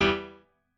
admin-leaf-alice-in-misanth…/piano34_1_001.ogg